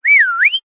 Звуки речи, голоса, пения
Мужской свист бодрый словно для привлечения внимания 3